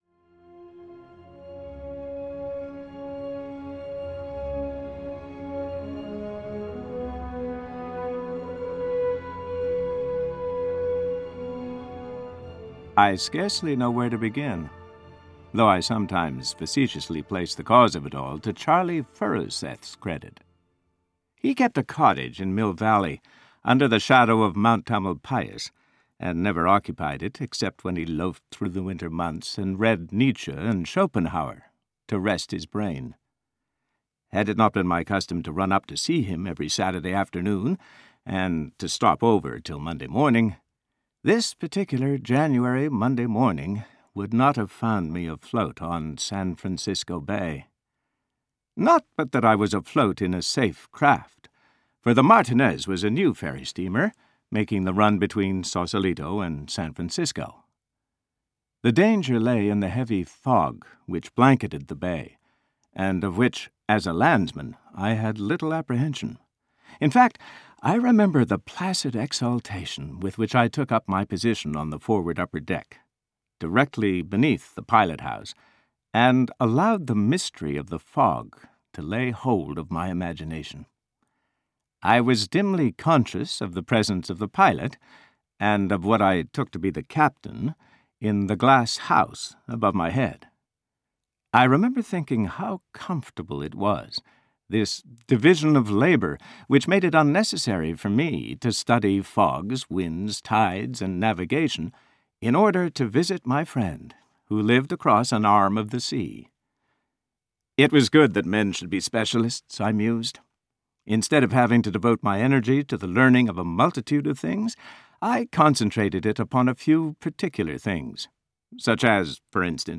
Audio kniha